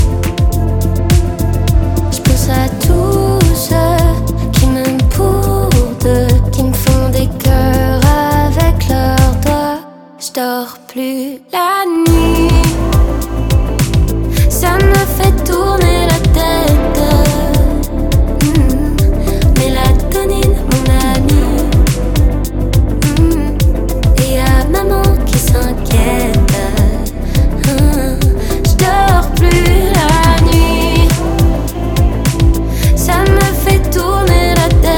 Pop
2025-03-14 Жанр: Поп музыка Длительность